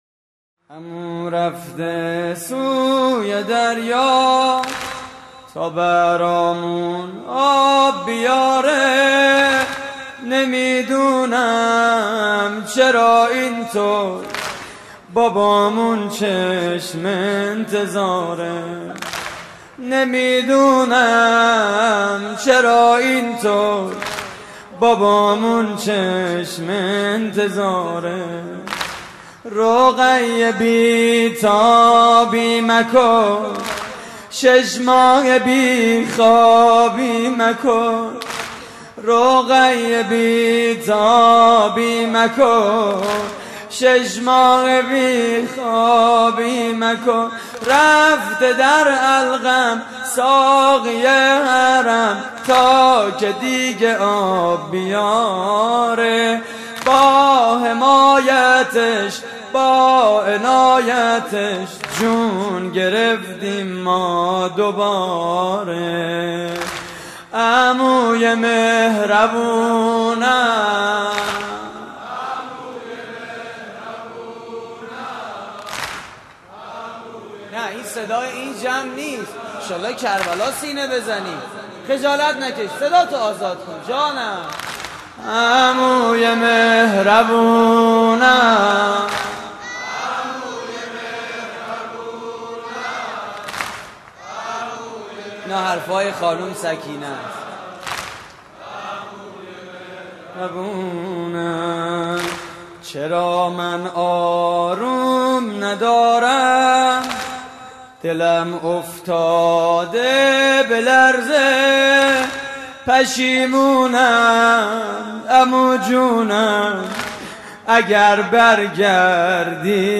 مراسم عزاداری ظهر تاسوعای حسینی (محرم 1433)